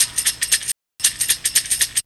ZG2BREAK10#5.wav